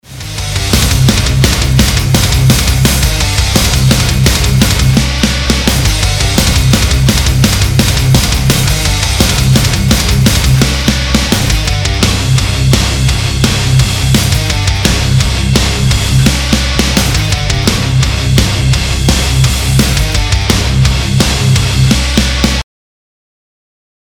Rate My Mix plz [Metalcore]